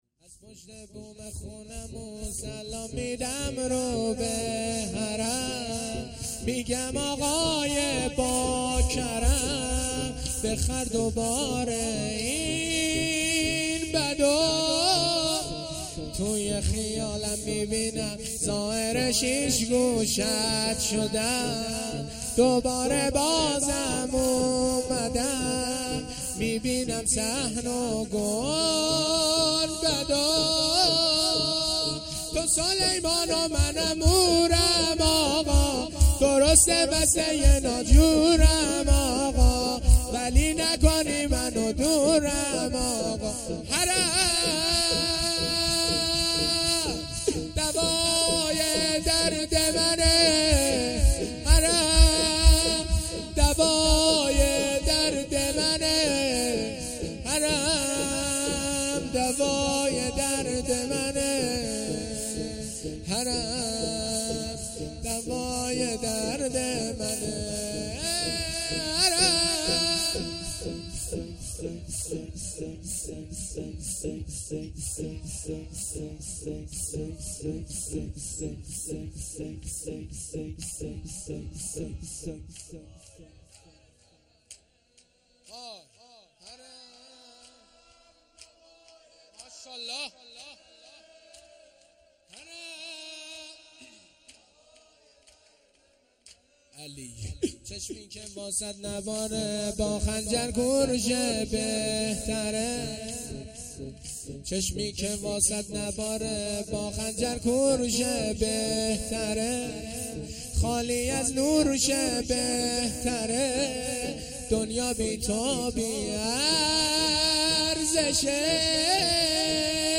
محرم الحرام ۱۴۴۳